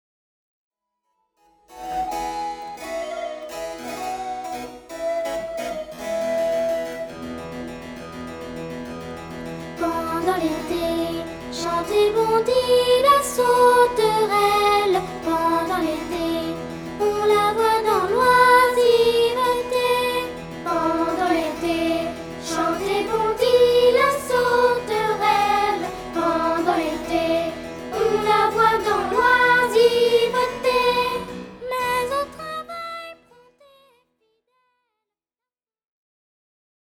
La musique baroque et renaissance à la portée des enfants.